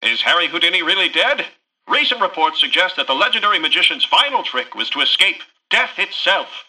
Newscaster_headline_05.mp3